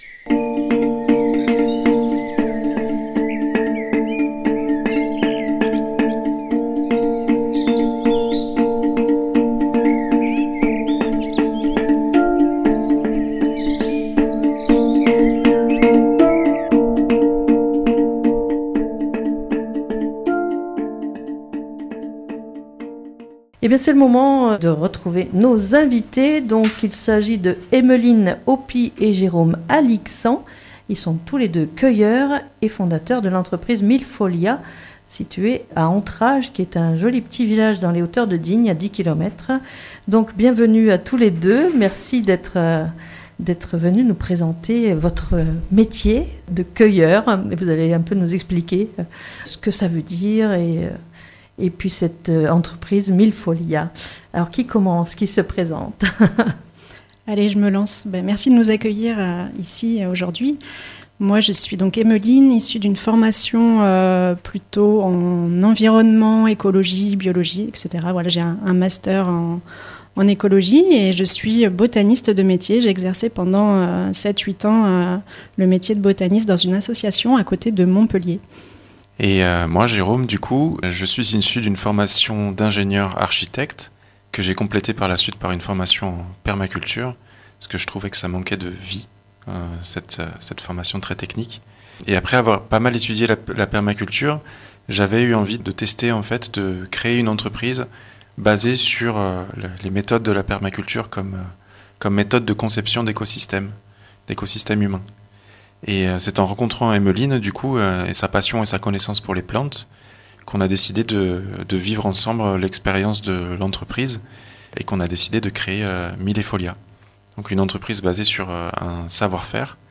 en direct de l'antenne Fréquence Mistral Digne